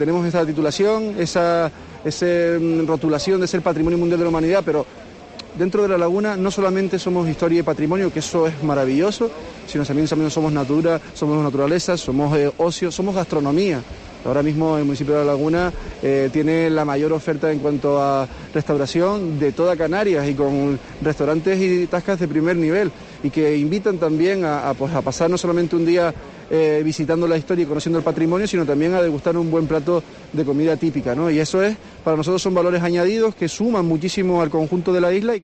Luis Yeray Gutiérrez, alcalde de La Laguna, en La Mañana en Canarias desde Fitur